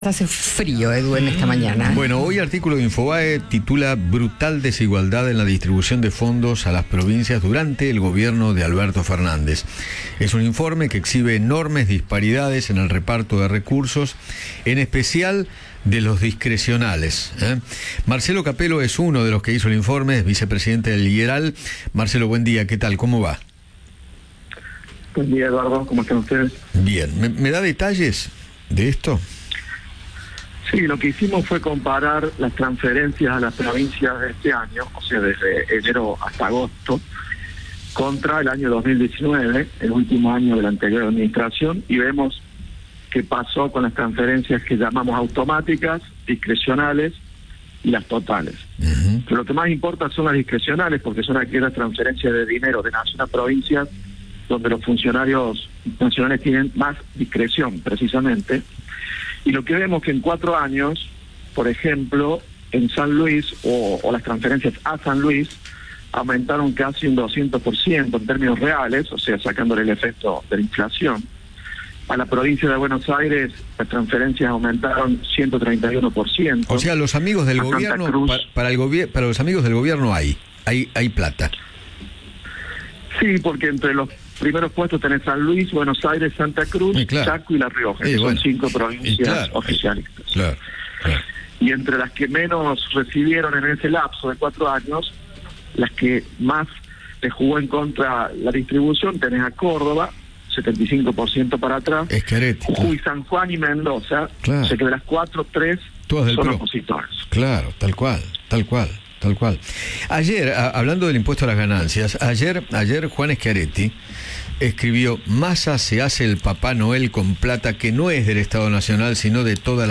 conversó con Eduardo Feinmann sobre el anuncio de Sergio Massa y se refirió a la desigualdad en la distribución a las provincias durante el gobierno de Alberto Fernández.